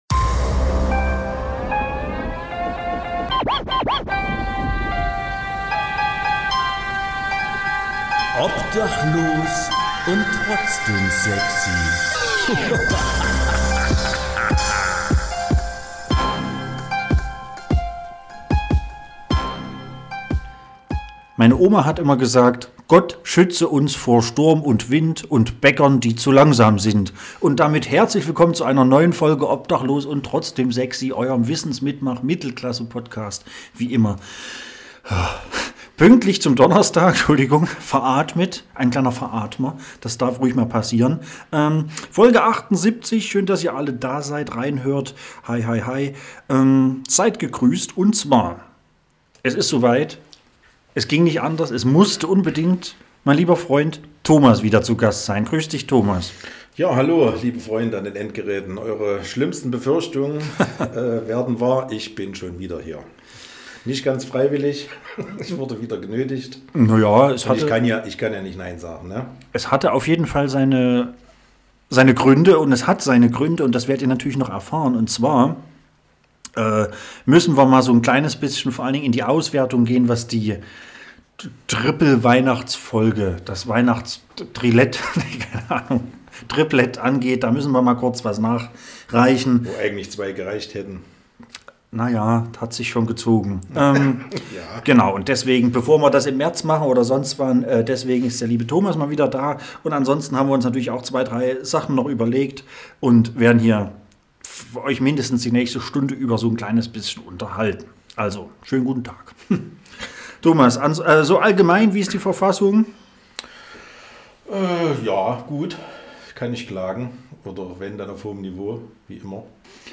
Mittelklasse-Podcast mit wöchentlich wechselnden Gästen, viel Unterhaltung und einer Menge Wissen!